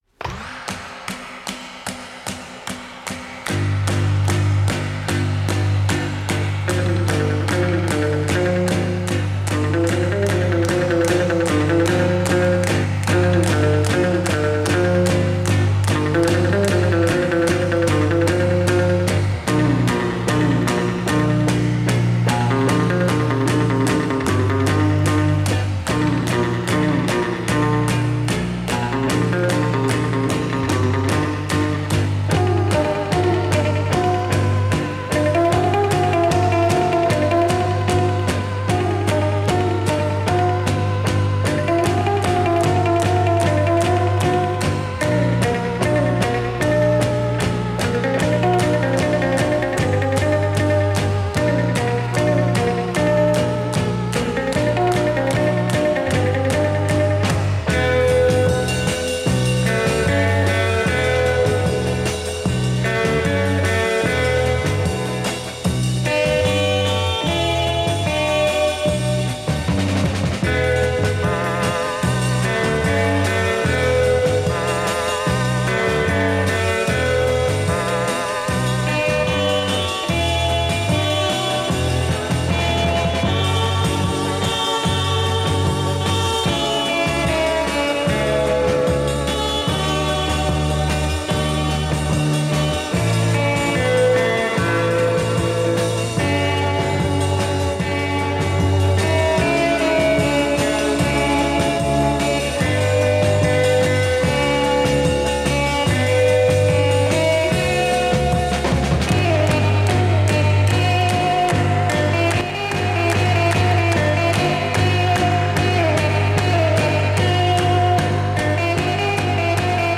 – Zur Geschichte des DDR-Jugendradios DT64 – Öffentliche Lesung